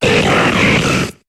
Cri de Registeel dans Pokémon HOME.